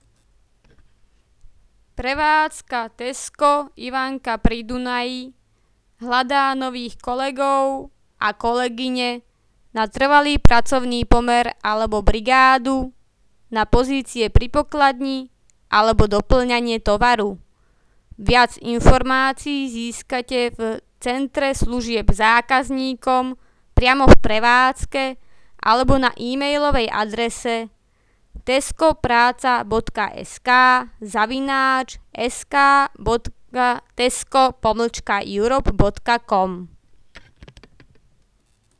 tesco_ivanka_pri_dunaji_komercne_hlasenie.wav